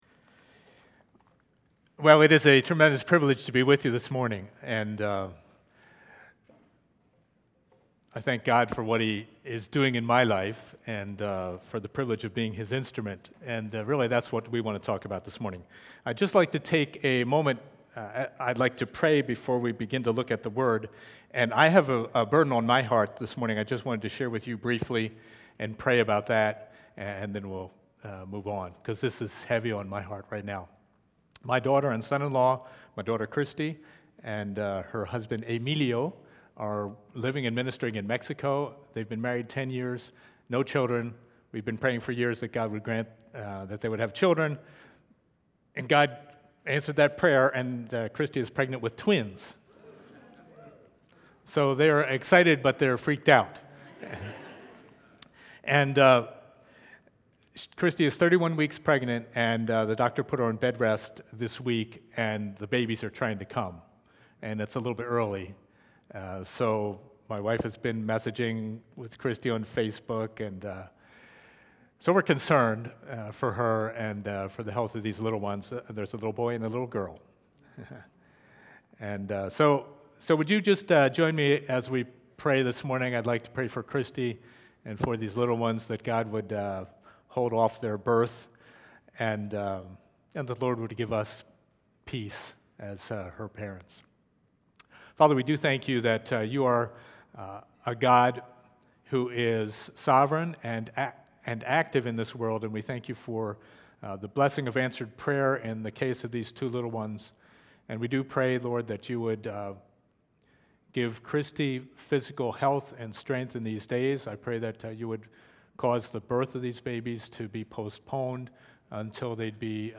Occasion: Mission Sunday